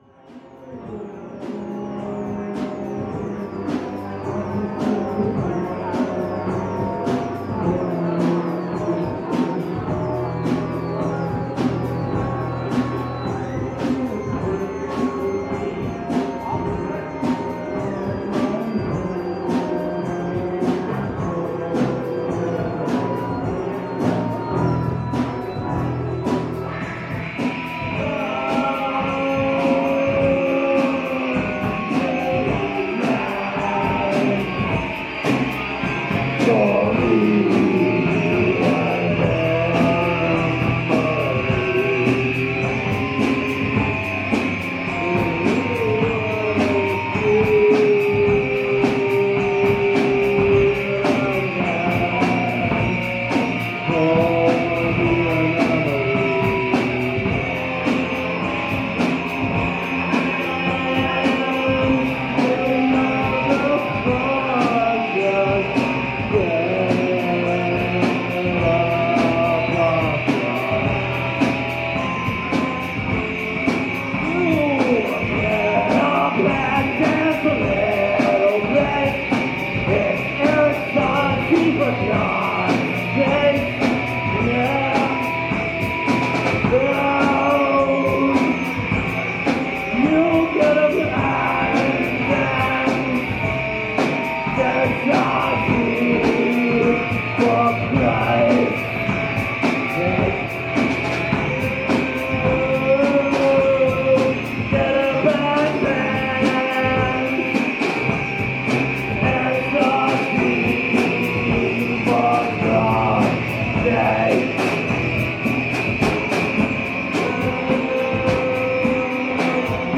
Canada NYC
As I took a break more people arrived, and the band seemed to get their second wind.